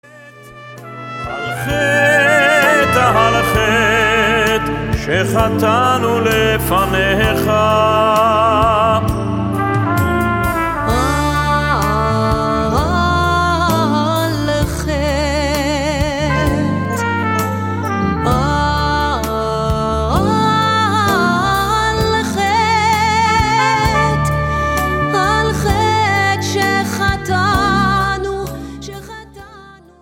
High Holy Day music
Traditional style with a modern flavor.